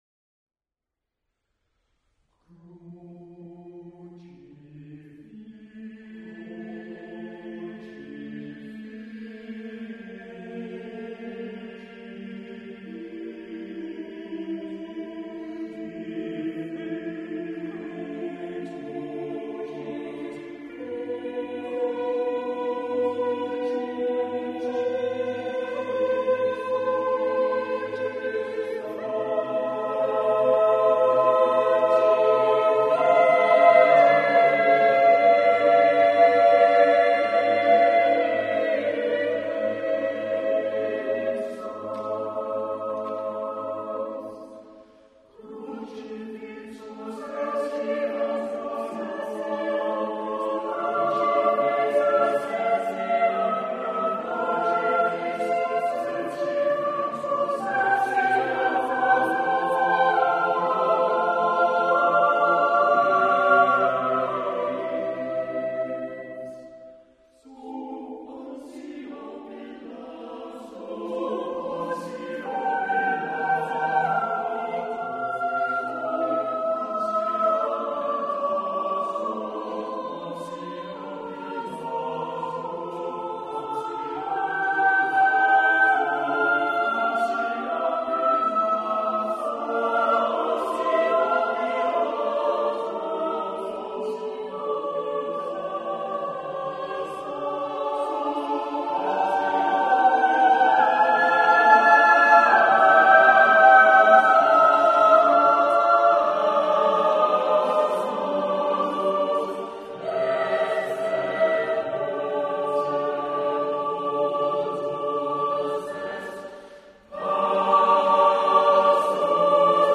Cherwell Singers Concert March 2007